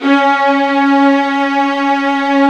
55u-va05-C#3.aif